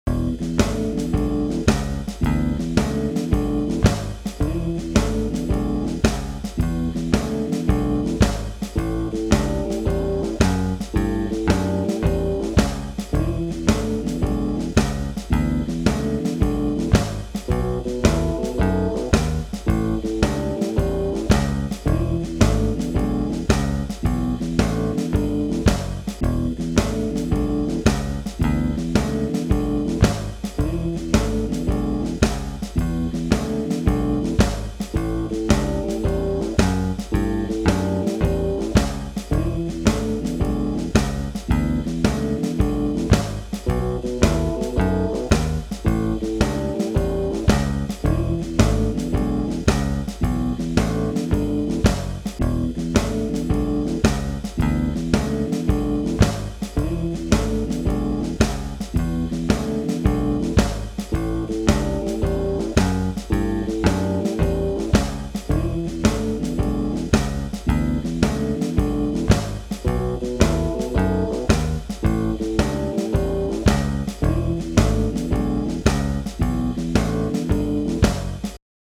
Blues in E (loop)